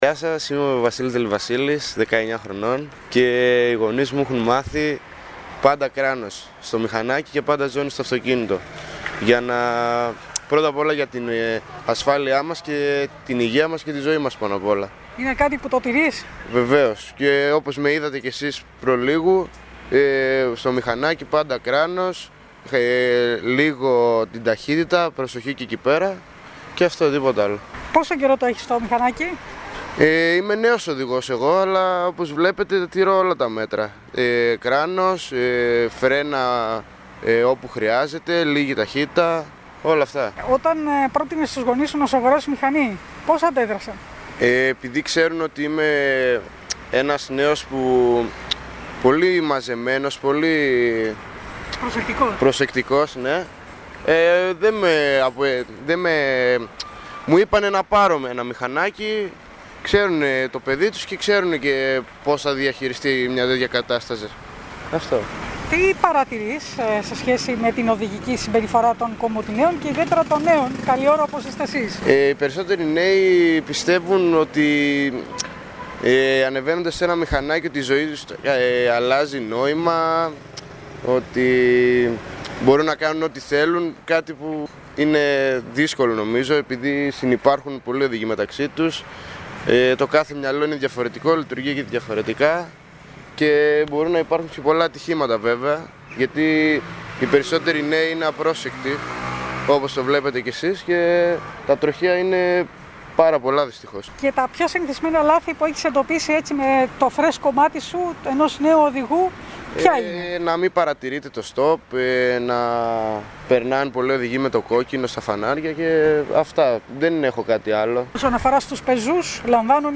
Μιλώντας στην ΕΡΤ Κομοτηνής και στην εκπομπή «Καθημερινές Ιστορίες» τονίζει πως είναι κάτι που το τηρεί πάντα.